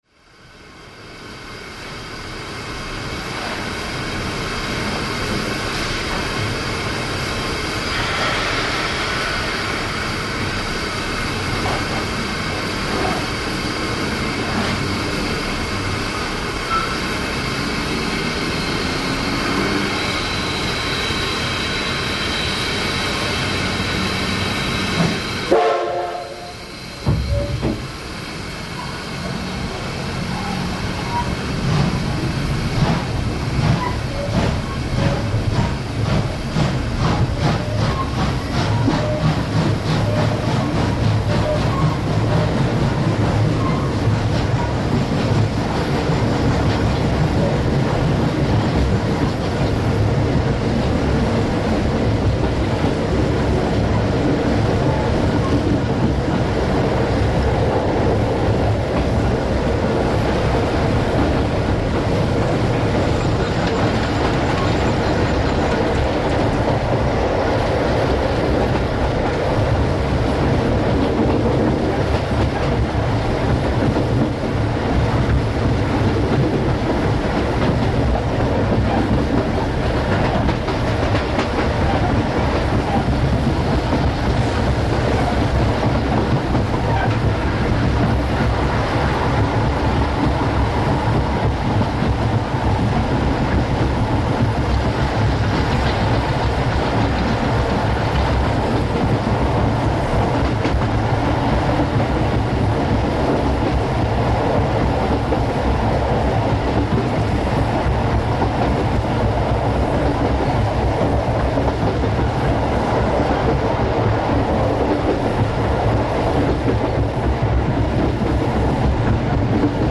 岩見沢行きの客車列車。機関車のすぐ後ろで録音しましたが、貨物と違って客車4両という軽い列車。ブラスト音はほとんど聞こえず
機関車の振動に振り回されてガタガタ揺れるばかりでした。
hok-D51kuriyama.mp3